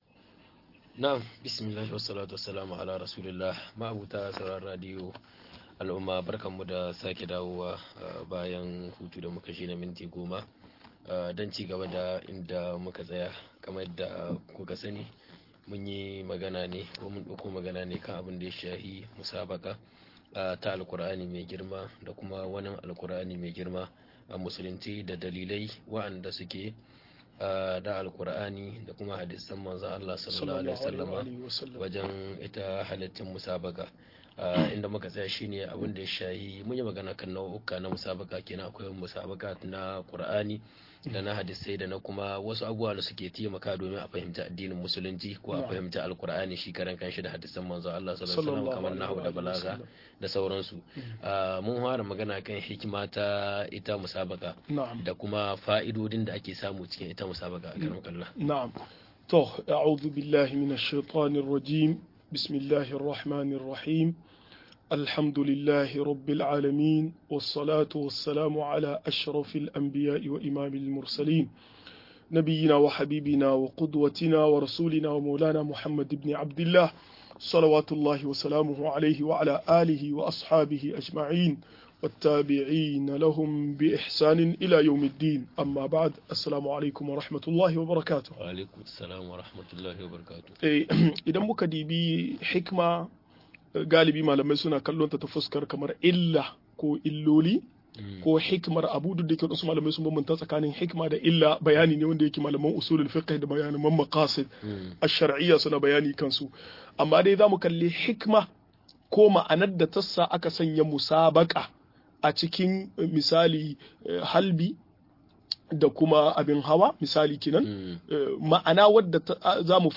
Bayani kan musabaqa-02 - MUHADARA